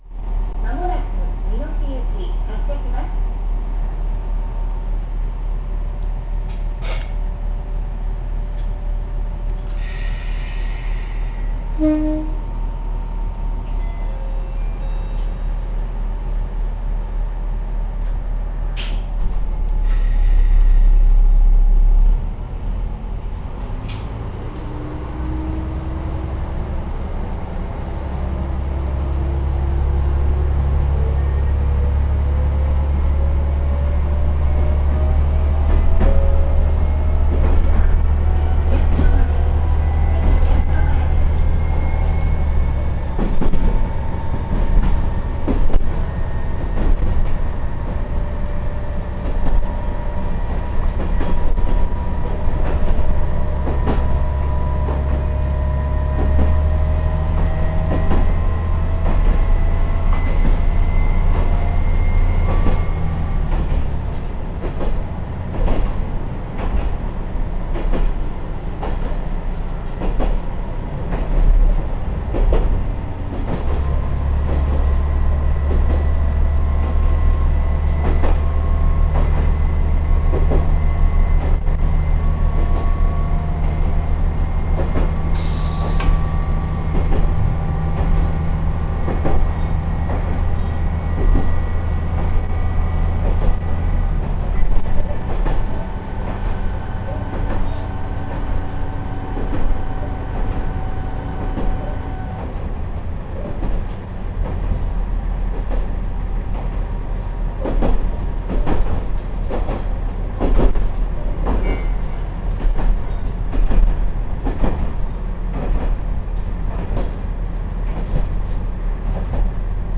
〜車両の音〜
・ナガラ500形走行音
【越美南線】関→関市役所前（2分27秒：804KB）
ごく普通の走行音ですが、ナガラ200形に乗った後だと大分静かに感じます。